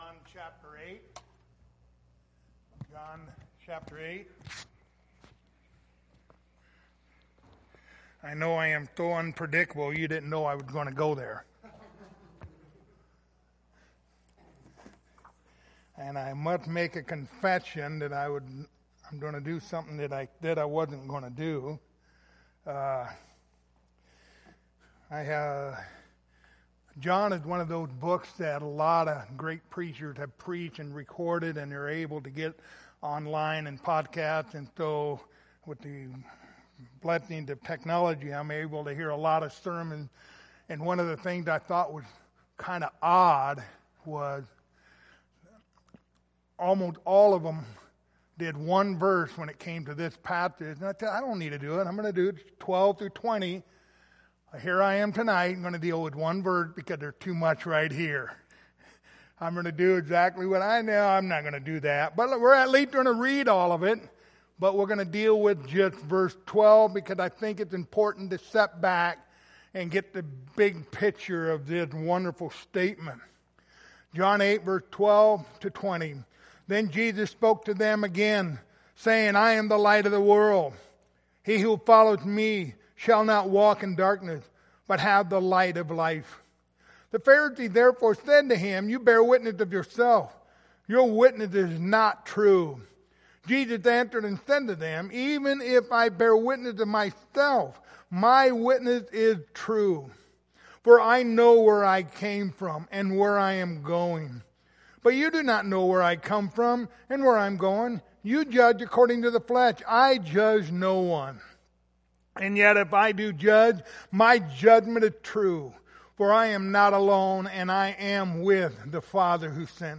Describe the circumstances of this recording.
Passage: John 8:12 Service Type: Wednesday Evening Topics